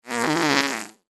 Звуки пука, пердежа
Звук пердежа с усилием мужчина постарался